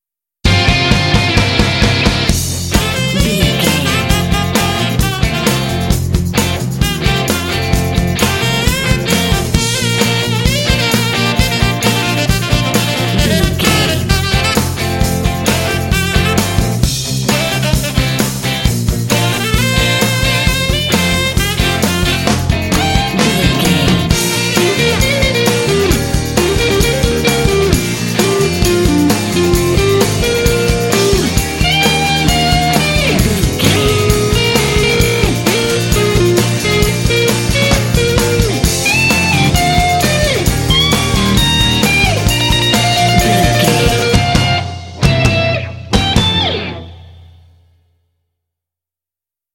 Uplifting
Ionian/Major
bouncy
happy
electric guitar
drums
bass guitar
saxophone